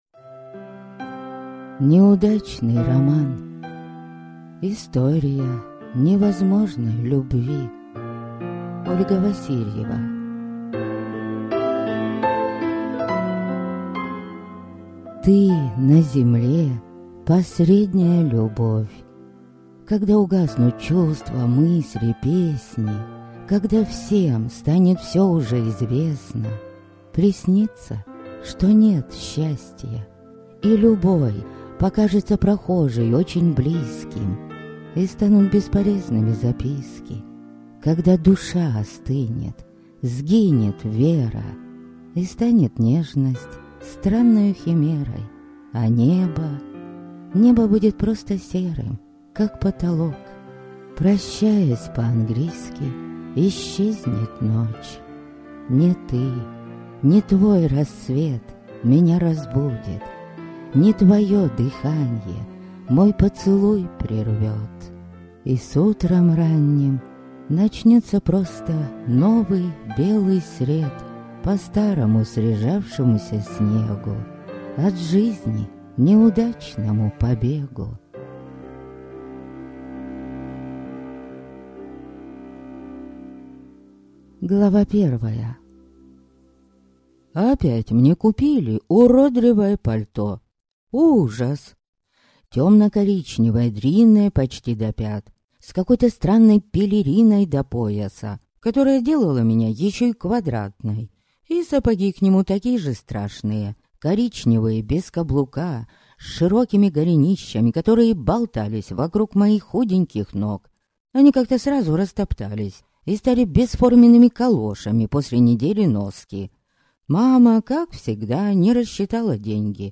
Аудиокнига Неудачный роман | Библиотека аудиокниг
Прослушать и бесплатно скачать фрагмент аудиокниги